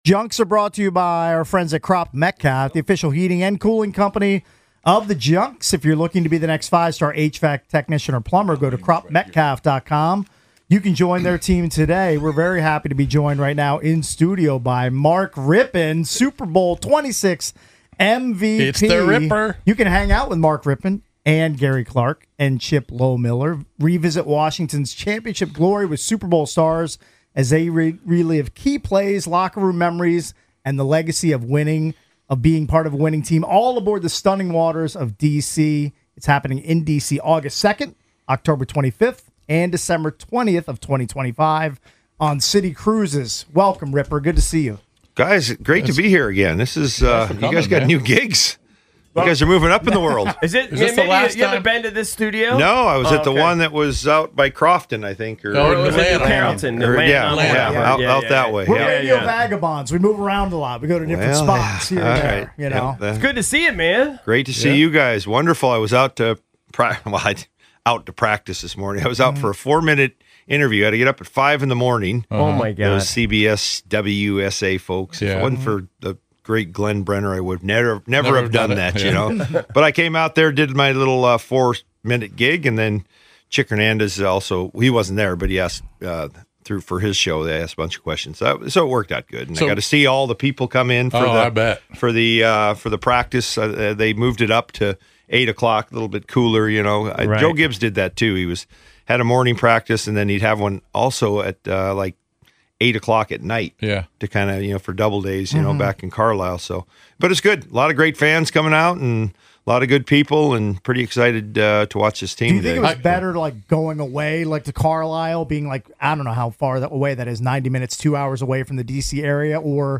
Mark Rypien Joins The Junkies (In Studio)